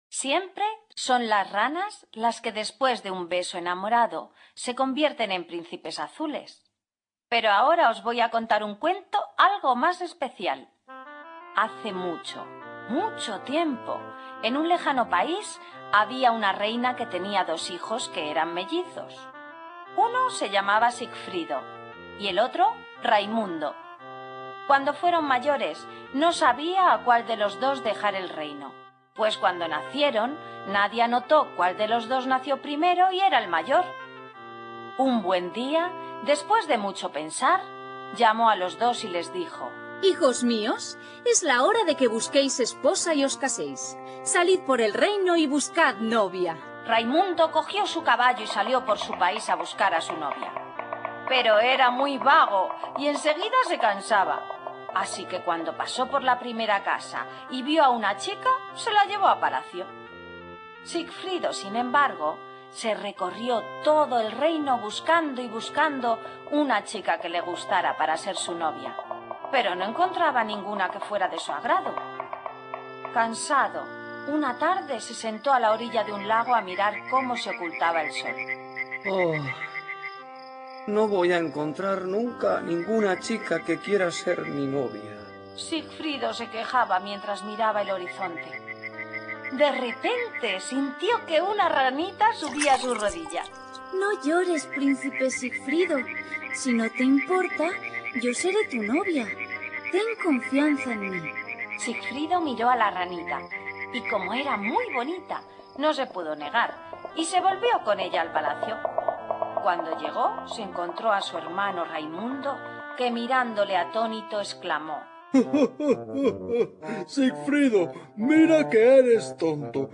Sonidos: Cuentos infantiles
Cuentos infantiles